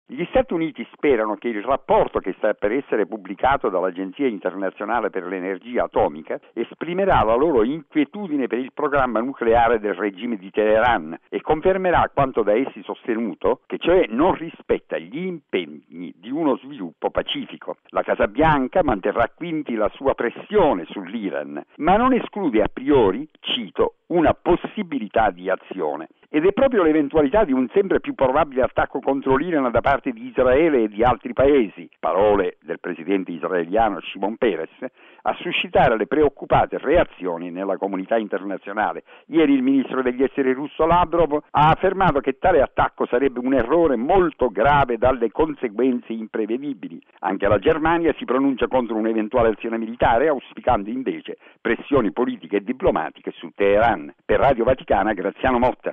Cresce l’attesa intorno al rapporto dell’Agenzia internazionale per l’energia atomica sul nucleare iraniano. Continuano le indiscrezioni sul documento, in cui si denuncerebbe che Teheran sarebbe pronta all'arma atomica. Il servizio